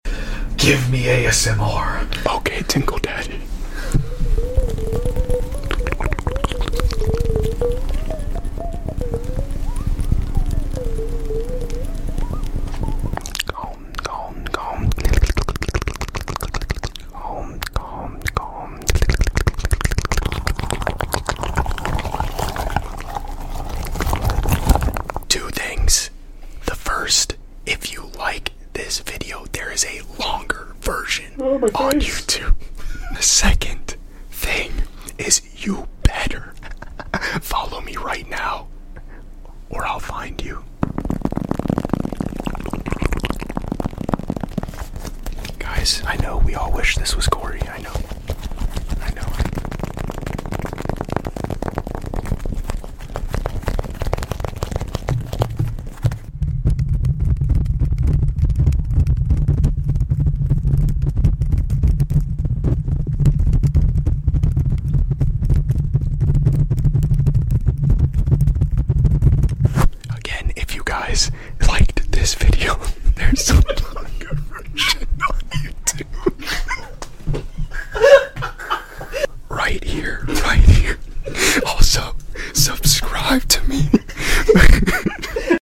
Ohhhh He GAVE me ASMR sound effects free download